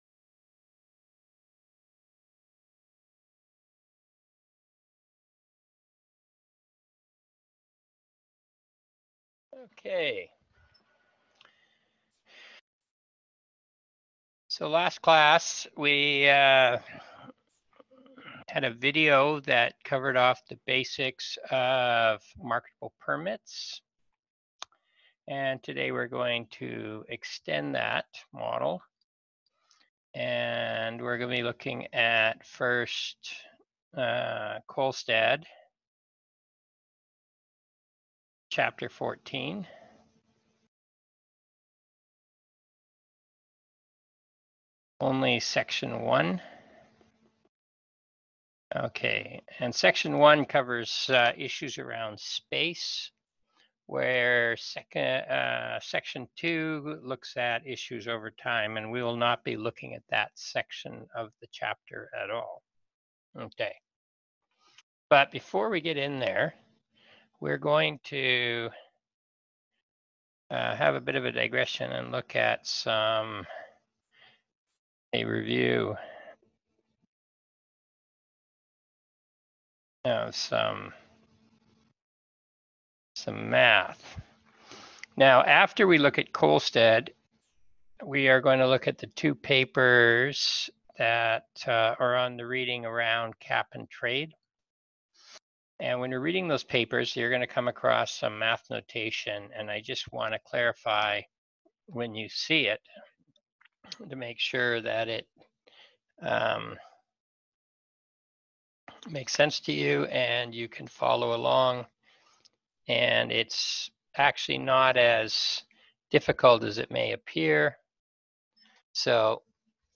460_Lecture_July27.mp4